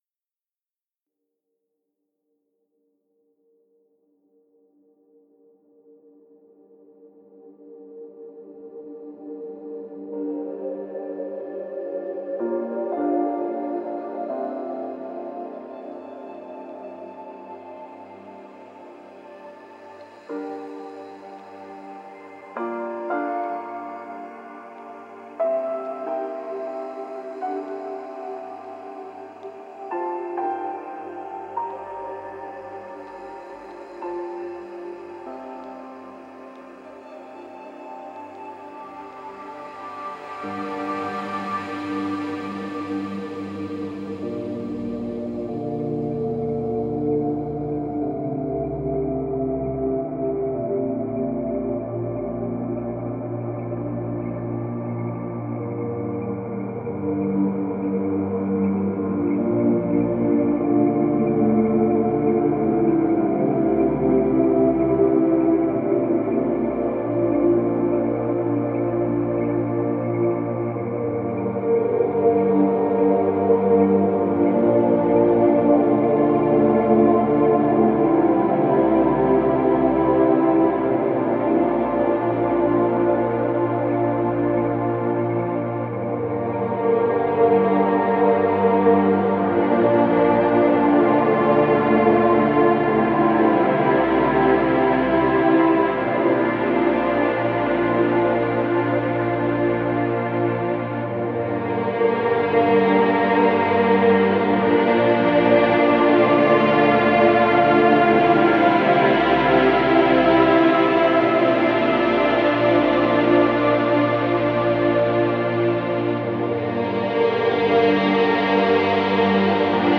Стиль: Chillout/Lounge / Ambient/Downtempo